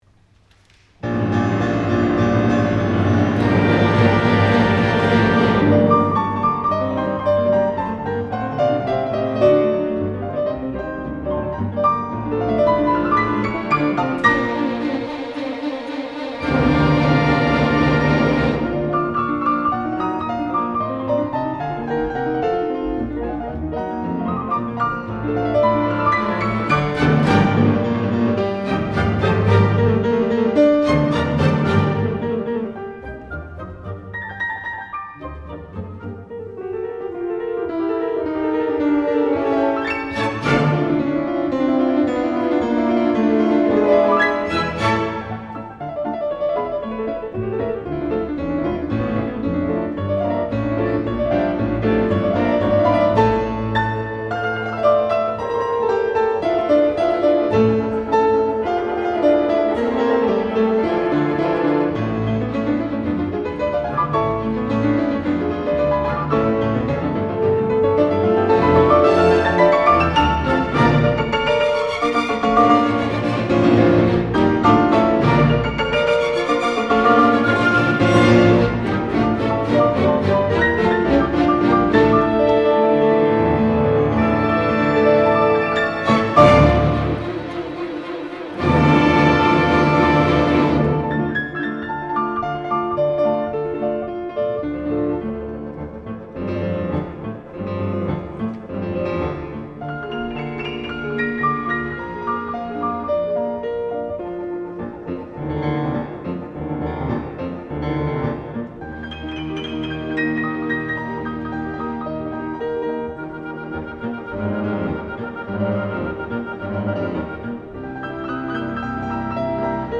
The orchestra is scored for 2 flutes, 2 oboes, 2 clarinets, 2 bassoons, 2 horns, 2 trumpets, timpani, crash cymbals and strings.
The third movement, marked Presto, is an energetic Tarantella in the key of G Minor. The music is extremely fast, featuring a strong triplet figure.
The movement gains in volume and ends in a flourish of G minor arpeggios.
I recently played this concerto in Cape Town with the Cape Philharmonic Orchestra in May 2010.